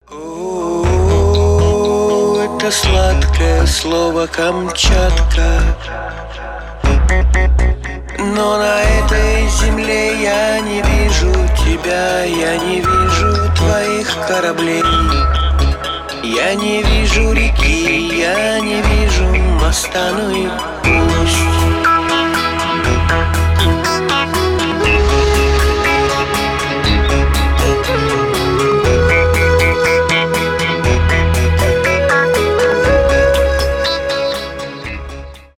акустика , рок
cover